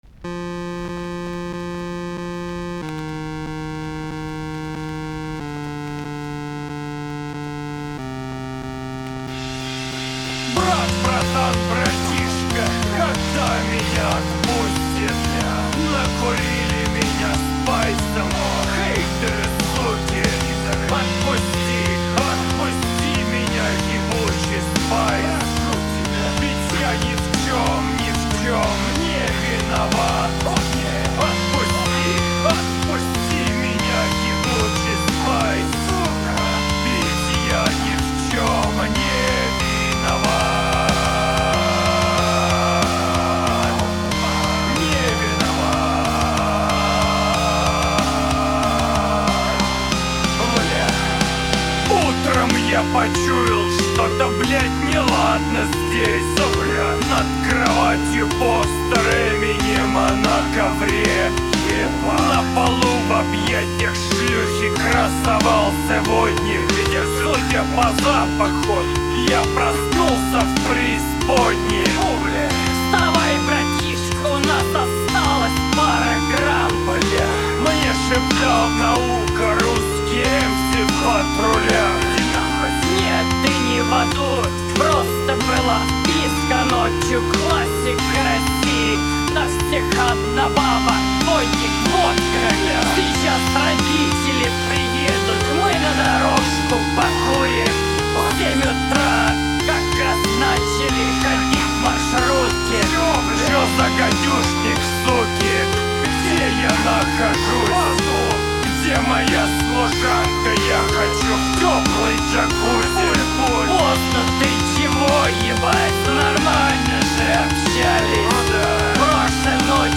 ну у тебя опять как-то пресно, ... две петли и пиздец. лучшеб ты тяжелых рифов сюда наебенил каких-нибудь